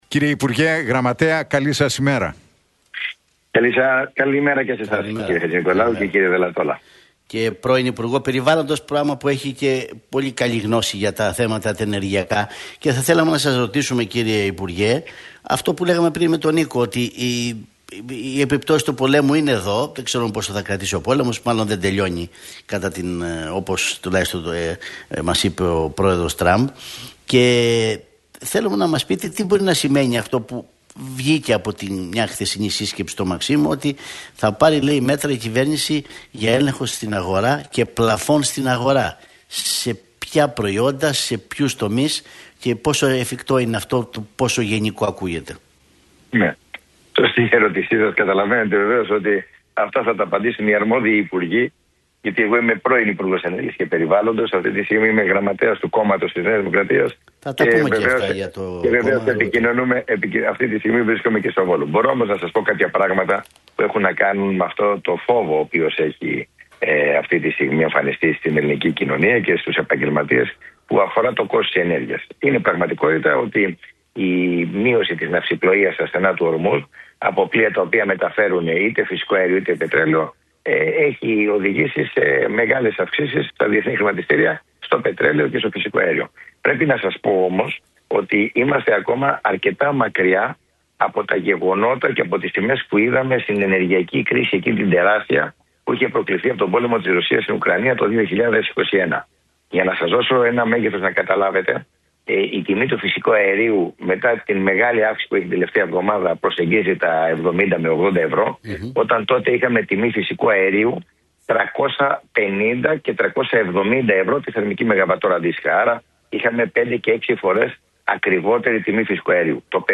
Την ετοιμότητα της κυβέρνησης να στηρίξει νοικοκυριά και επιχειρήσεις εξέφρασε ο γραμματέας της Πολιτικής Επιτροπής της ΝΔ Κώστας Σκρέκας, μιλώντας στον